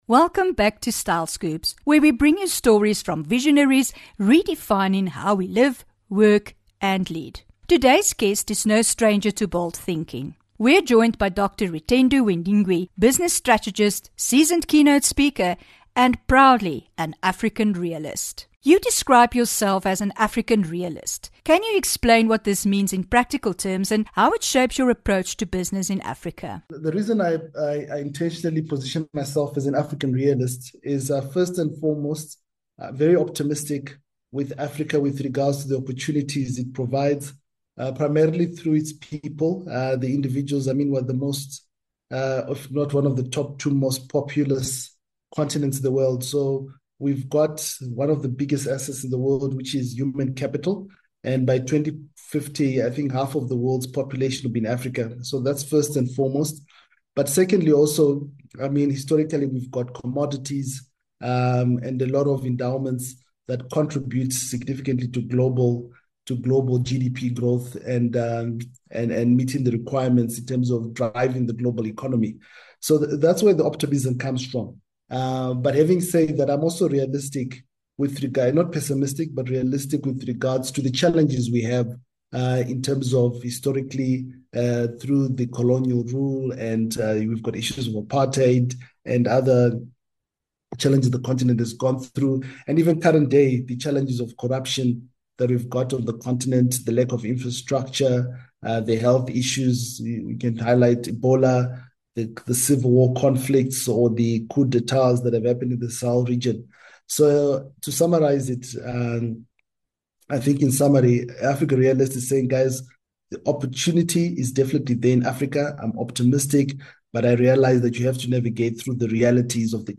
25 Jun INTERVIEW